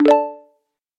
Звук входа всплывающее окно